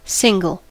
single: Wikimedia Commons US English Pronunciations
En-us-single.WAV